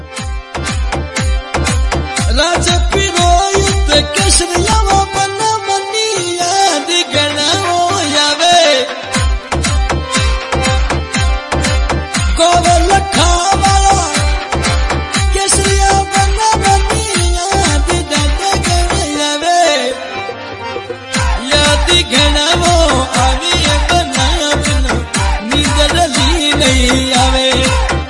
Category: Bhojpuri Ringtones